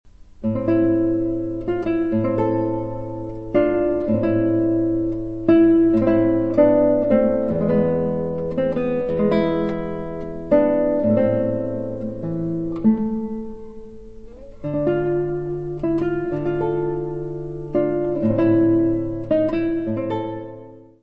: stereo; 12 cm
Área:  Música Clássica
transcribed for solo guitar
Più tosto largo. Amorosamente.